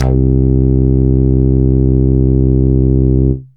SYNTH BASS-2 0006.wav